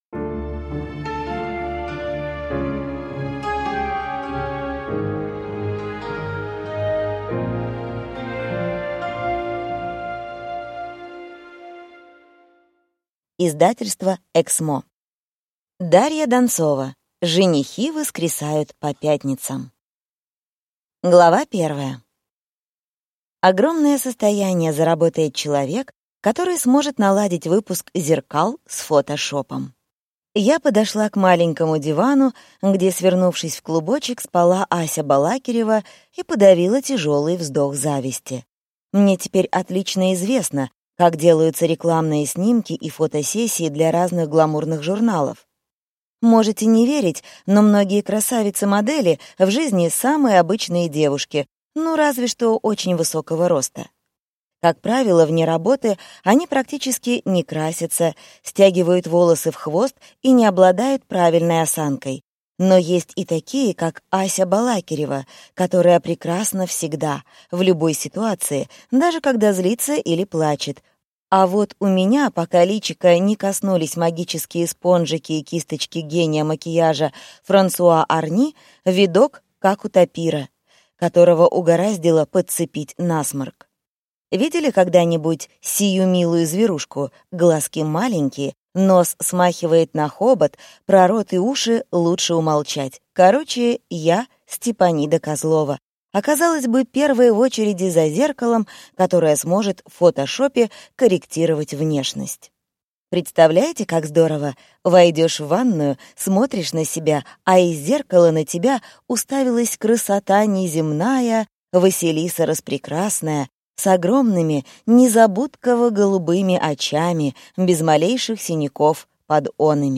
Аудиокнига Женихи воскресают по пятницам - купить, скачать и слушать онлайн | КнигоПоиск